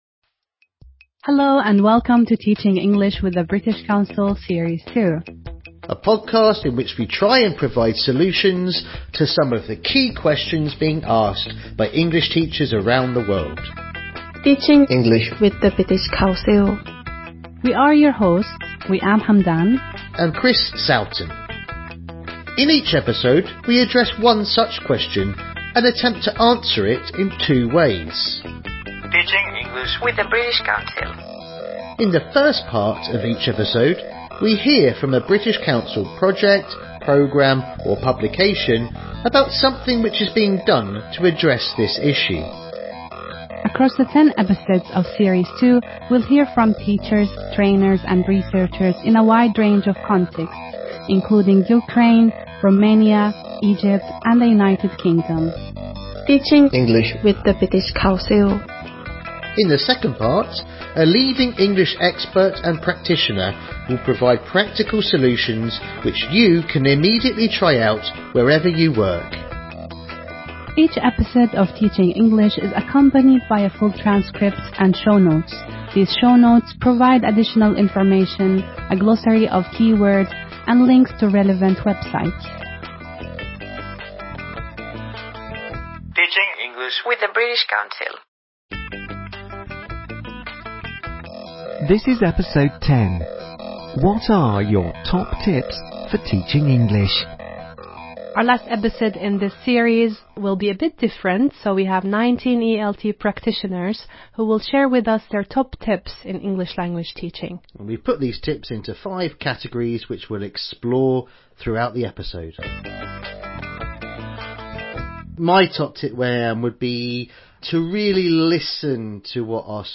British Council: TeachingEnglish - series 2 overview In our second series of this ten-part podcast series from the British Council, we try and provide solutions to some of the key questions being asked by English teachers around the world. Each episode explores a specific topic through interviews, a focus on recent developments and reports on British Council initiatives in English language teaching.